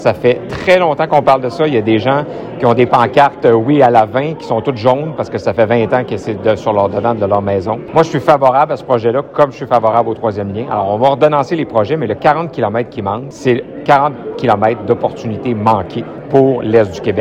Charles Milliard s’est prononcé sur de nombreux sujets locaux dimanche lors d’une rassemblement militant à Saint-Alexandre-de-Kamouraska.